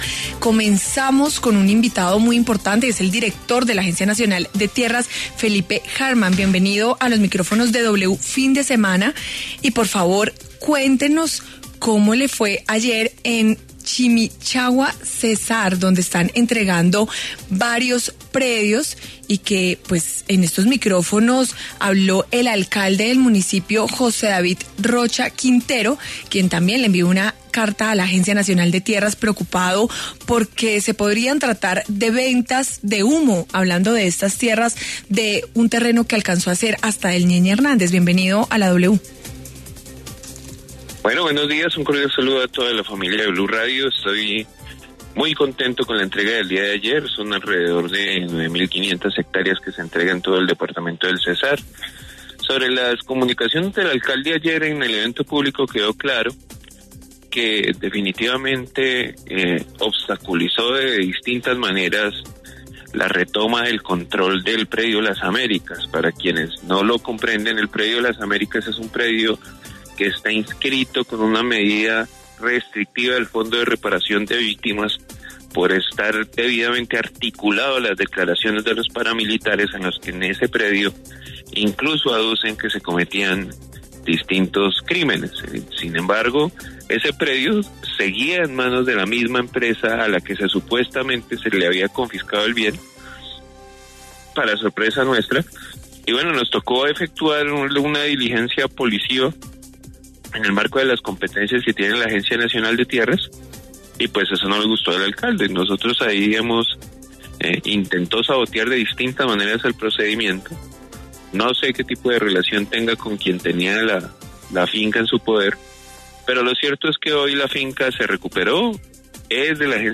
El director de la Agencia Nacional de Tierras, Felipe Harman, pasó por los micrófonos de W Fin de Semana habló sobre la entrega de el polémico predio La América que tiene un valor de más 20.000 millones y está ubicado en Chimichagua, Cesar.